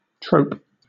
Ääntäminen
Southern England RP : IPA : /tɹəʊp/ IPA : [tɹ̥əʊp]